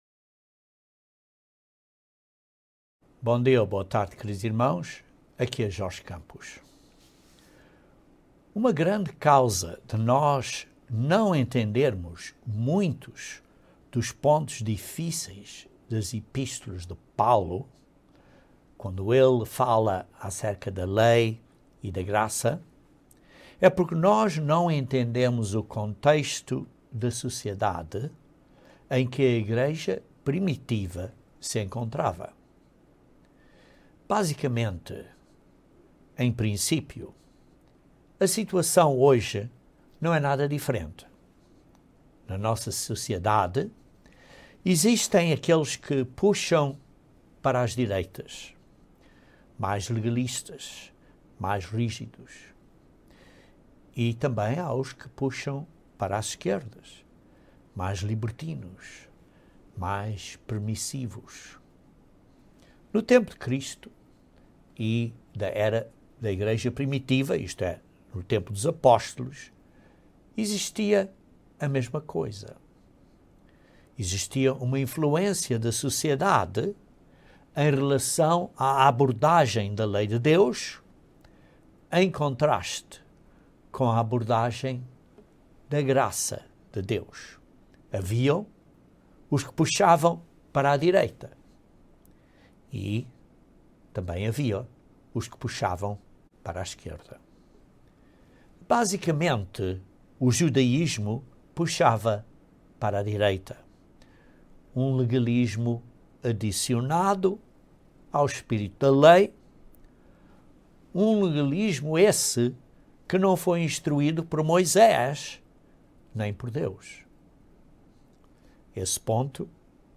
Este sermão descreve como este engano de Satanás que procede da Babilônia foi "reformado" para o "cristianismo" actual.